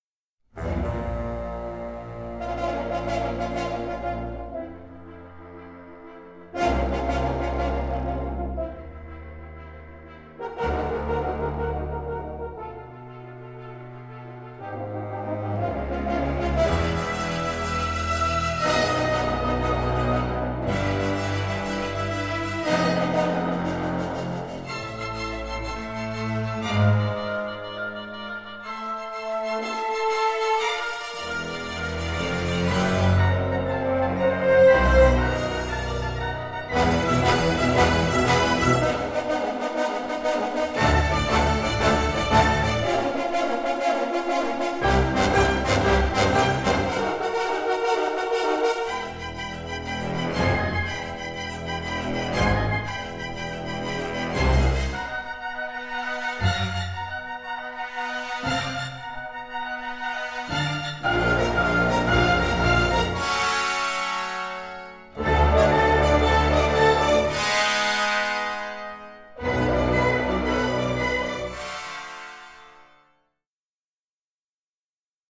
with powerful brass writing
Both scores were recorded in January 2023